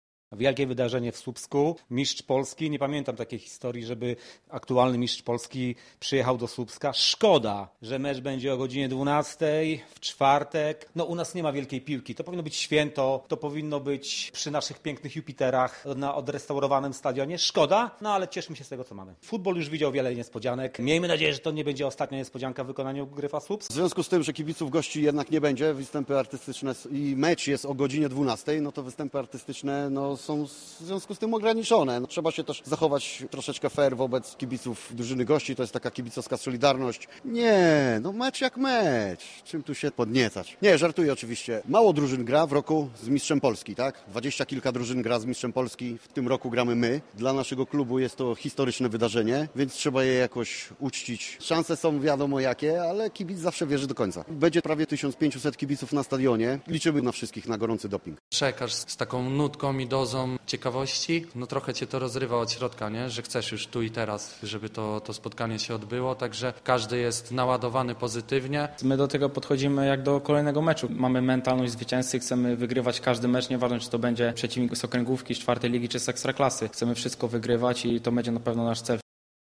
Posłuchaj rozmowy z kibicami: